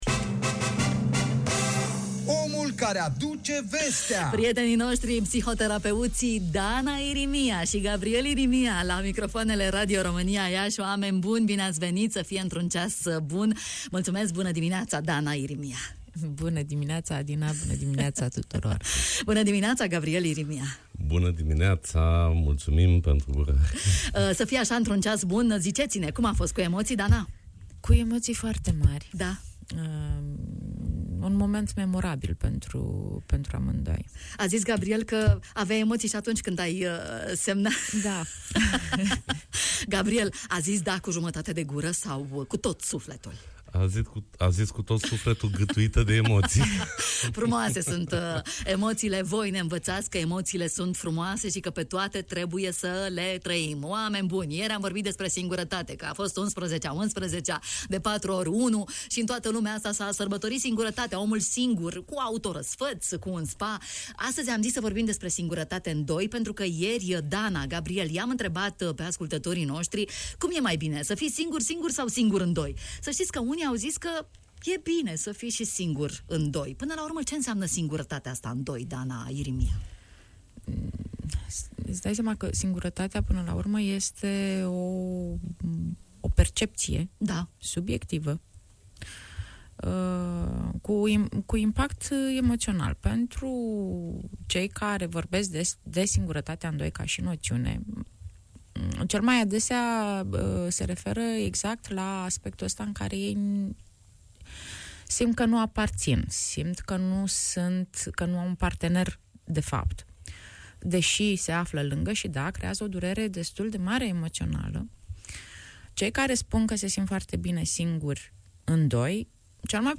Singurătatea în doi, cauze și soluții! Am aflat în matinalul Radio România Iași de la specialiștii în terapie de cuplu: